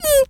pgs/Assets/Audio/Animal_Impersonations/mouse_emote_05.wav at master
mouse_emote_05.wav